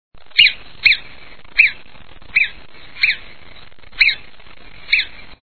Sonido de la marmota
marmota.wav